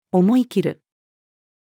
思い切る-female.mp3